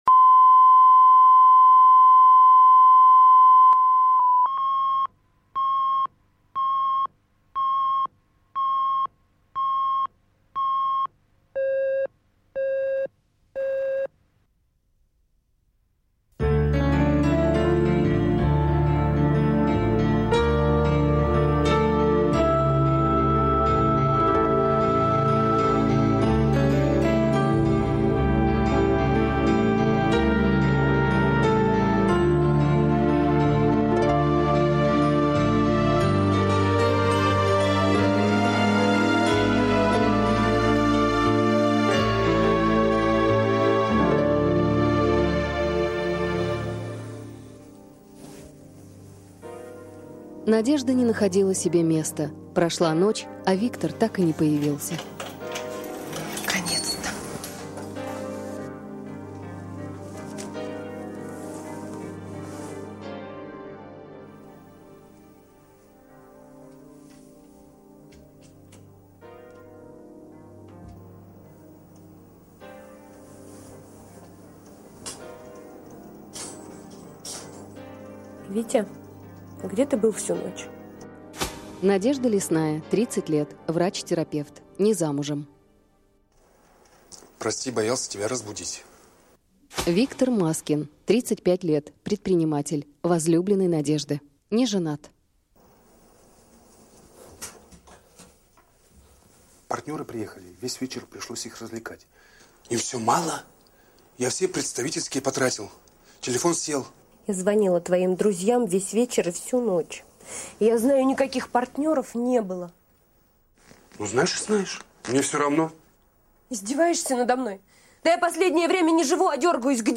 Аудиокнига Такая игра